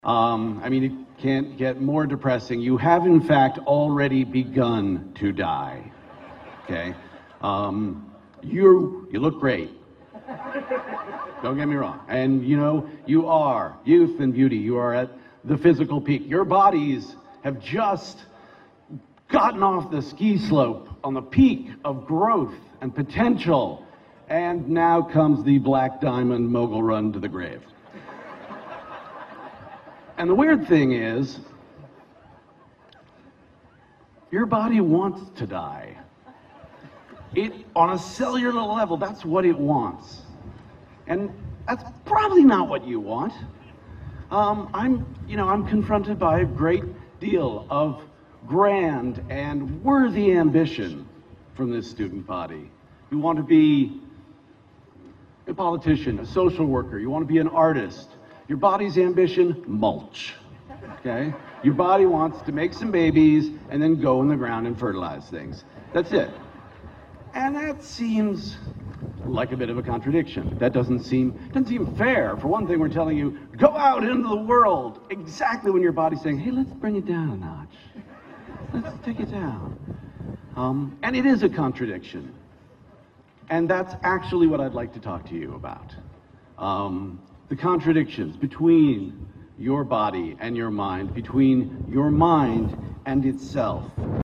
公众人物毕业演讲 第242期:乔斯韦登2013卫斯理大学(2) 听力文件下载—在线英语听力室